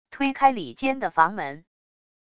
推开里间的房门wav音频